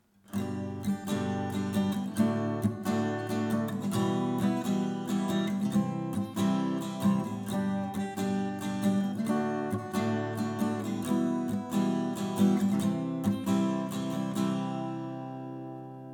und unten kannst Du hören, wie sie mit einem einfachen Schlagmuster klingen.
I – V – vi – IV (G-Dur)
I-–-V-–-vi-–-IV-G-Dur.mp3